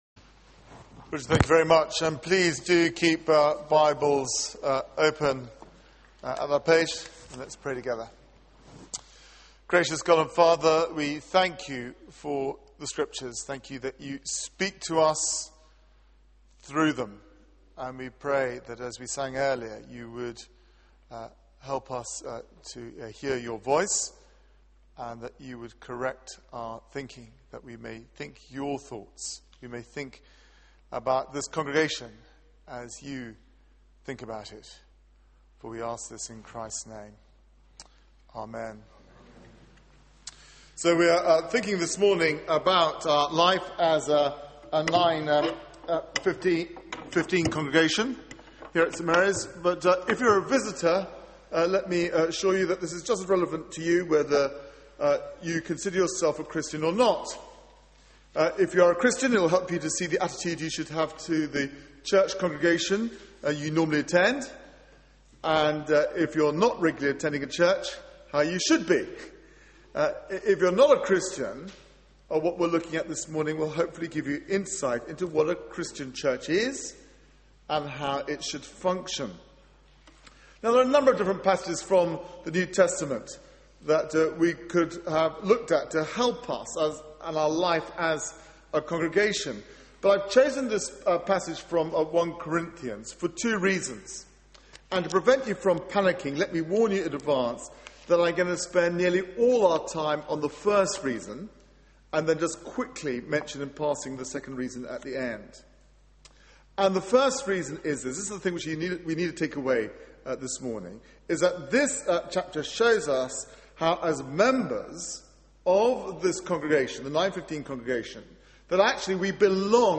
Media for 9:15am Service on Sun 08th Jan 2012 09:15 Speaker
Theme: Body matters Sermon